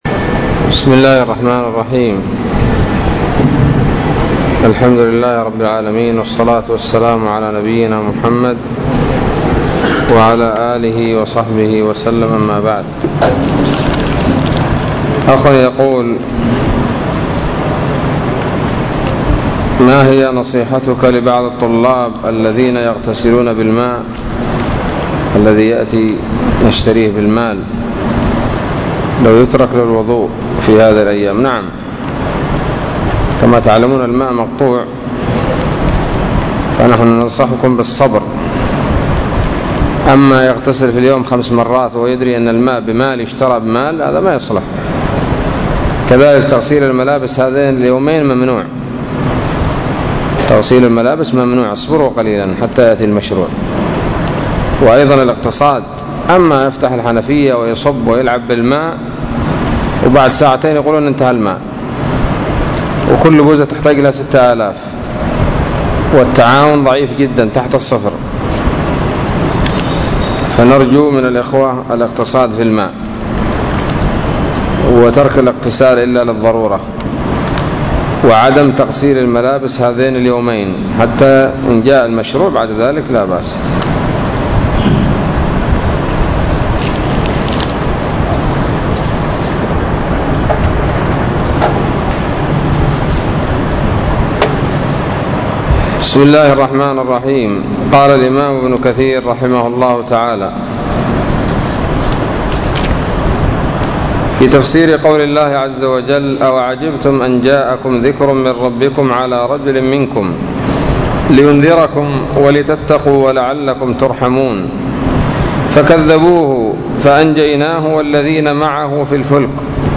الدرس السابع والعشرون من سورة الأعراف من تفسير ابن كثير رحمه الله تعالى